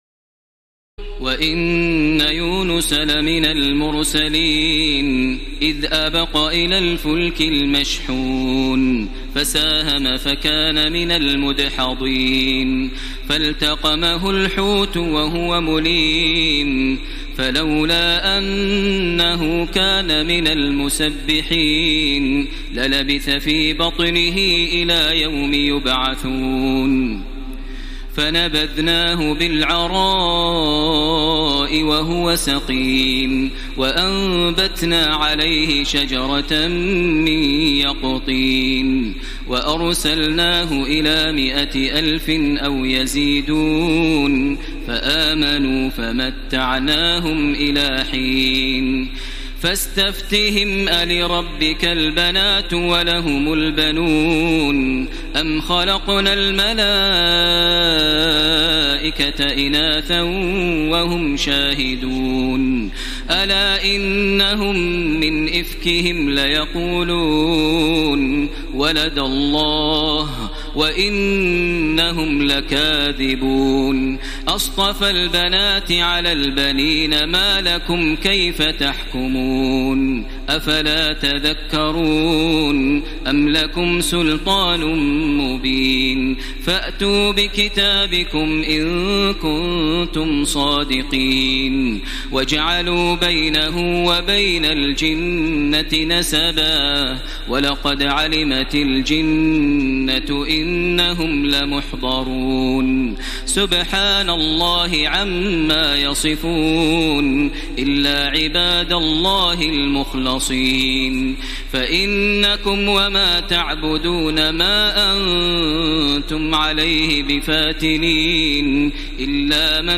تراويح ليلة 22 رمضان 1434هـ من سور الصافات (139-182) وص و الزمر (1-31) Taraweeh 22 st night Ramadan 1434H from Surah As-Saaffaat and Saad and Az-Zumar > تراويح الحرم المكي عام 1434 🕋 > التراويح - تلاوات الحرمين